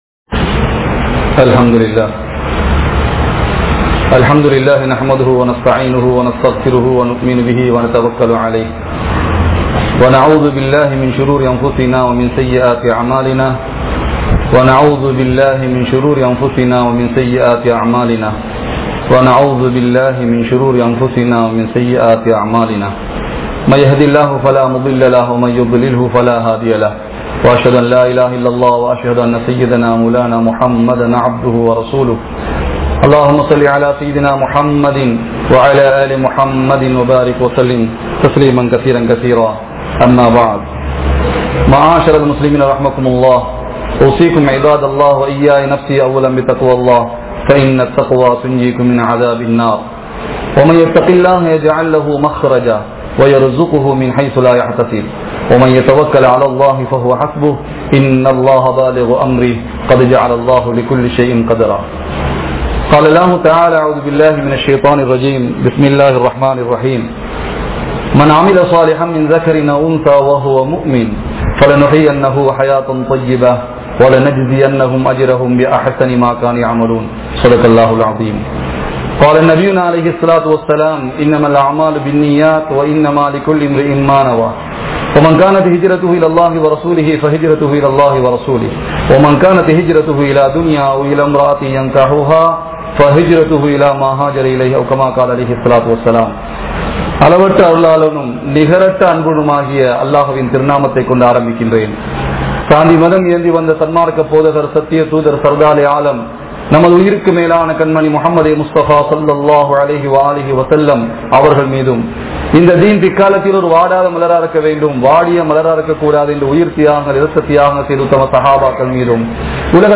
Allah`vai Maranthu Vidaatheerhal (அல்லாஹ்வை மறந்து விடாதீர்கள்) | Audio Bayans | All Ceylon Muslim Youth Community | Addalaichenai